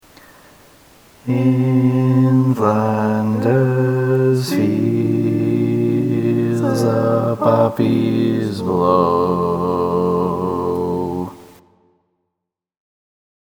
Key written in: F Major
Nice gentle 4-part suspension-chord tag